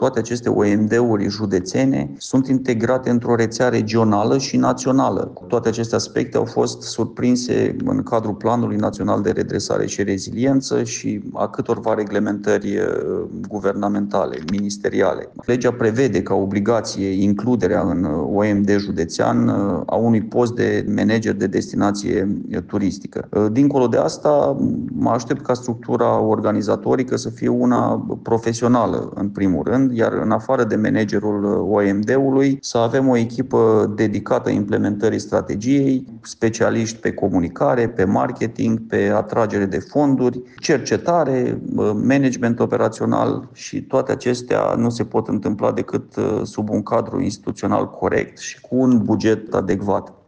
Noua organizație poate încheia parteneriate publice-private și are obligația de a elabora o strategie și un plan de acțiune în maximum un an, a precizat Daniel Juravle: